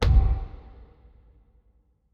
Fantasy Click (1).wav